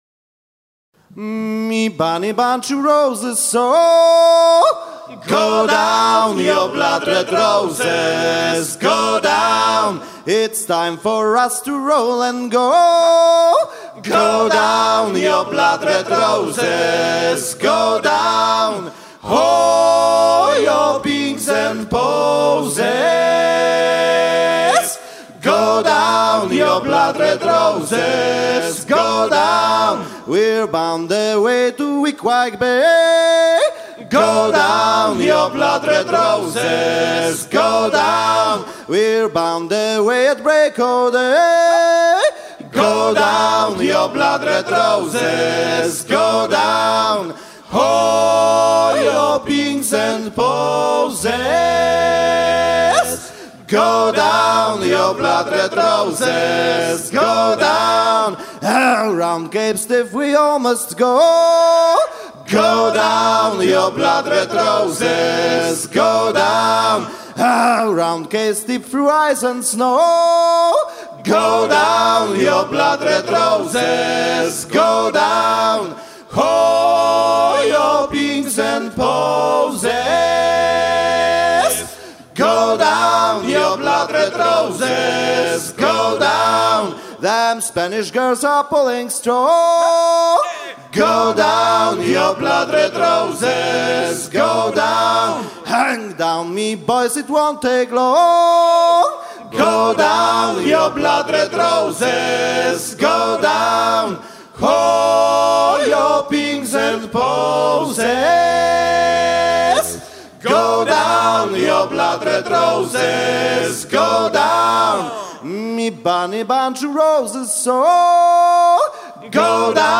chantey américain de cap-hornier mené par un groupe polonais
à hisser main sur main